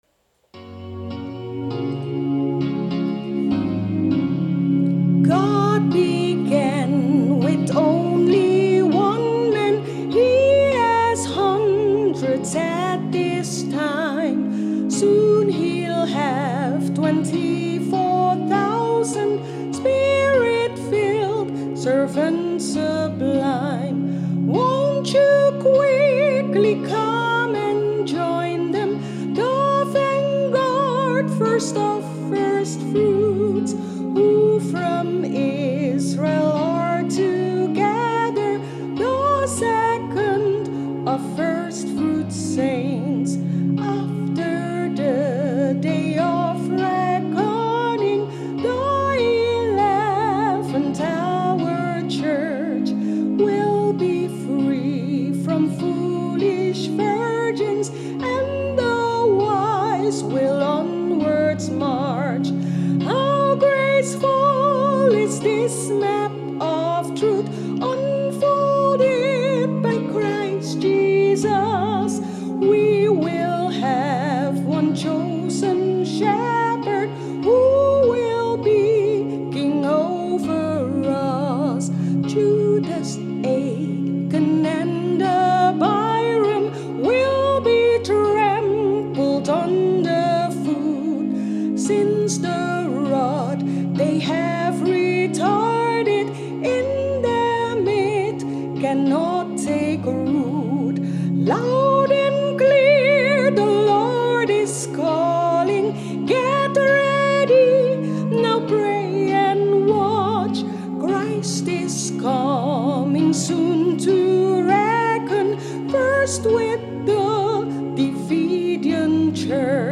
Hymn No. 21: